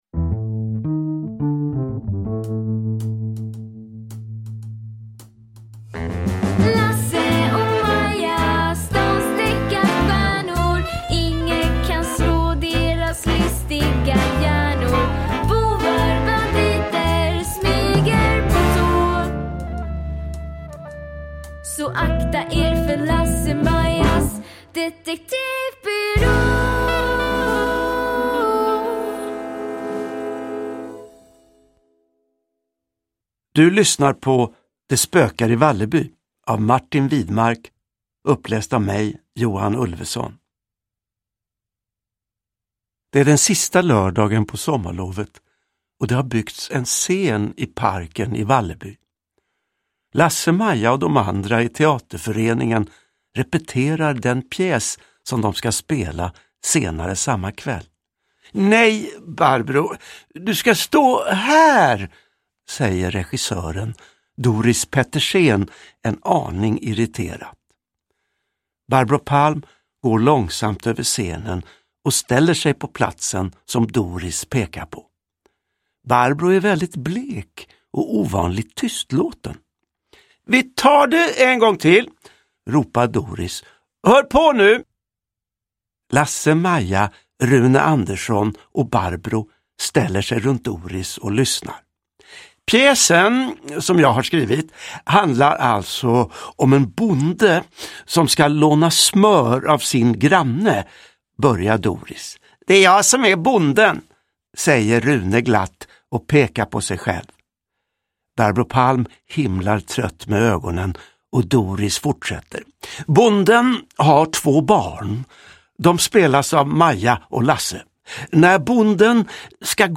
Uppläsare: Johan Ulveson
Ljudbok